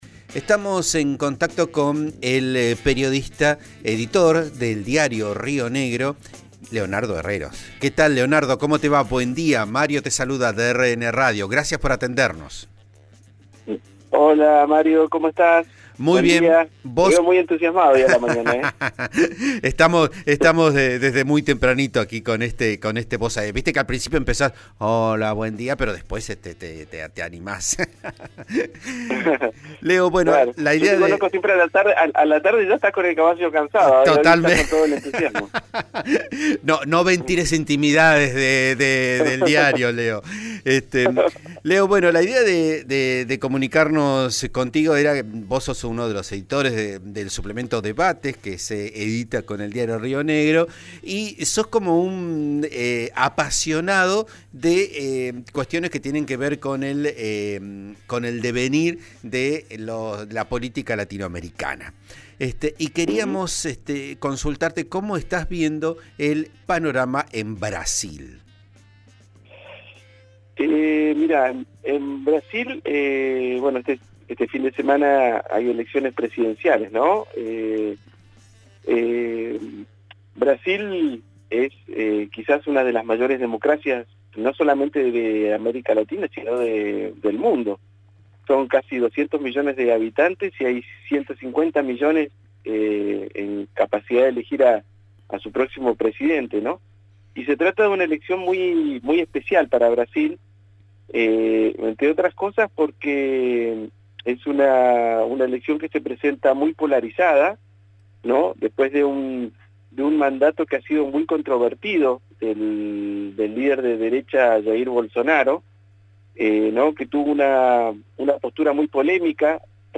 Análisis: elecciones en Brasil con muertos y ataques al sistema electoral